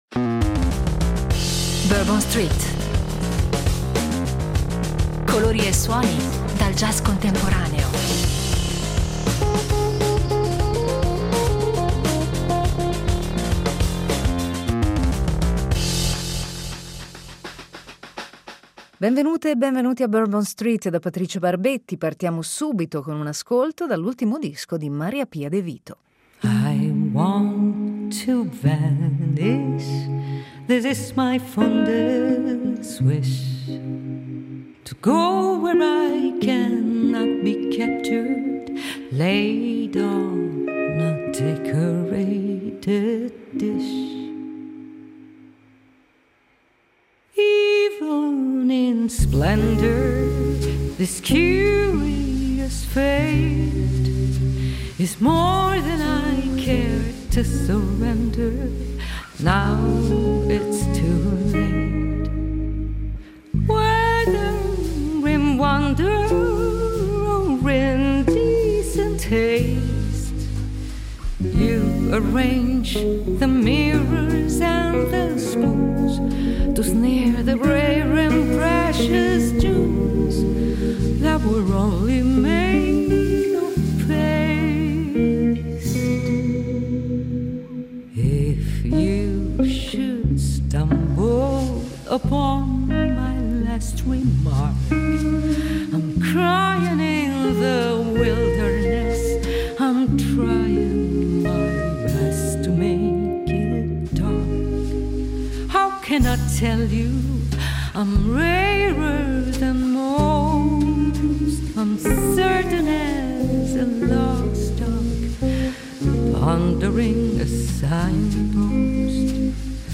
Da quando esiste il jazz, Bourbon street ne è diventata l’emblema: jazz tradizionale, carico di dramma e di vita, New Orleans insomma.